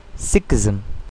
Sikhism (IPA: ['siːkɪz(ə)m] or
['sɪk-]; Template:Lang-pa, sikkhī, IPA: ['sɪk.kʰiː]) is a religion which began in sixteenth century Northern India with the teachings of Nanak and nine successive human gurus.